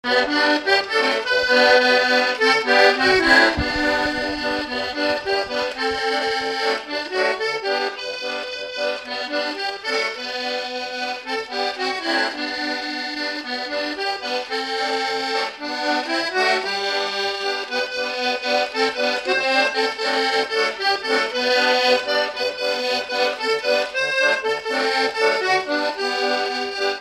Séga
Instrumental
danse : séga
Pièce musicale inédite